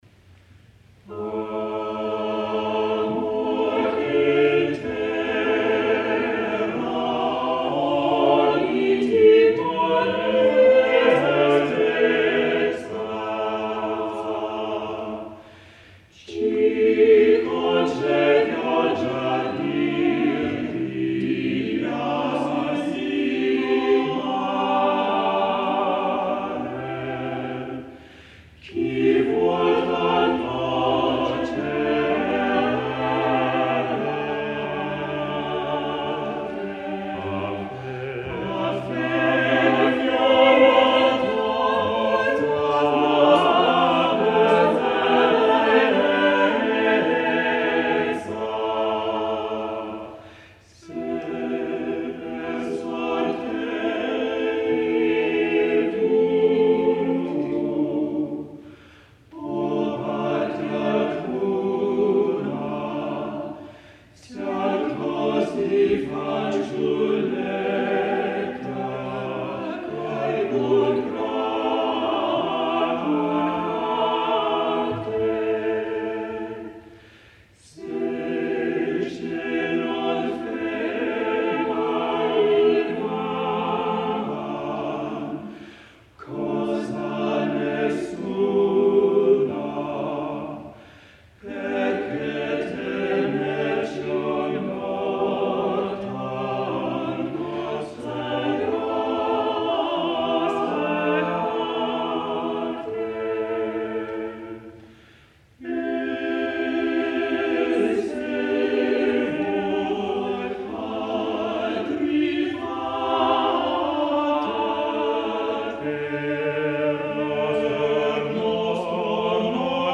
| Vocal Ensemble, 'Food of Love' 1991